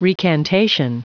Prononciation du mot recantation en anglais (fichier audio)
Prononciation du mot : recantation